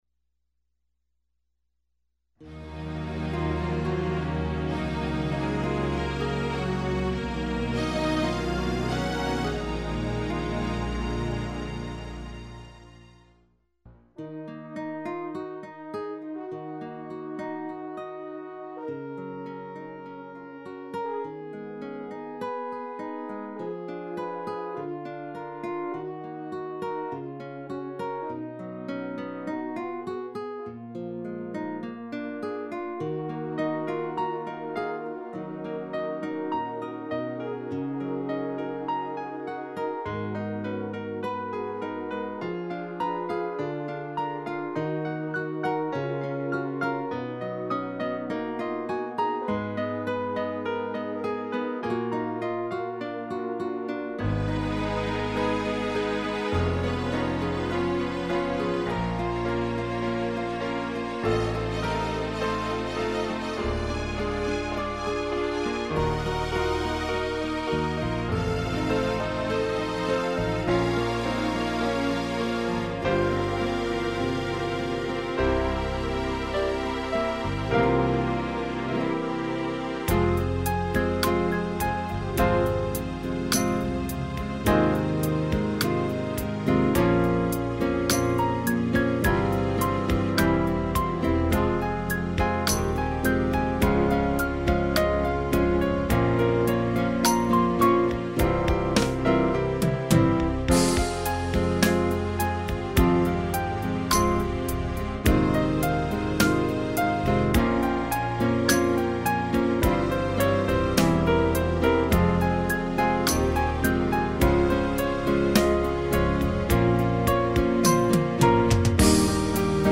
◊ Фонограммы: